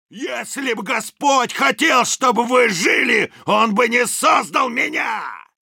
Звук с голосом солдата Если бы Господь хотел, чтобы вы жили, он не создал бы меня